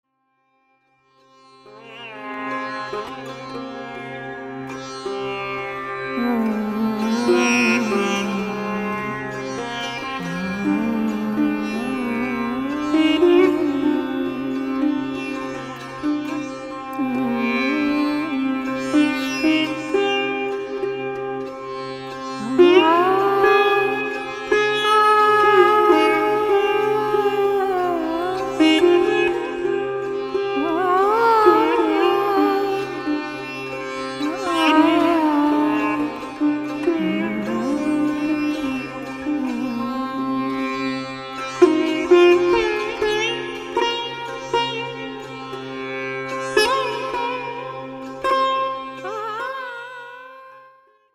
Genre: World Fusion.
sitar
vocals